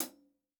TC Live HiHat 02.wav